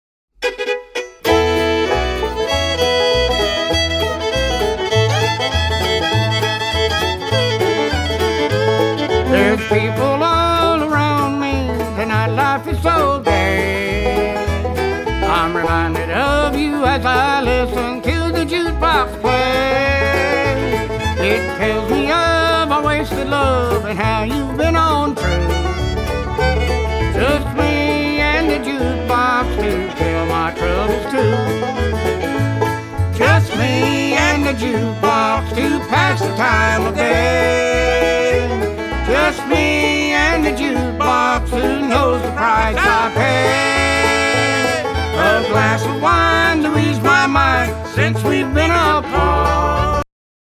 Genre: Bluegrass, Honky-Tonk, Gospel, Christmas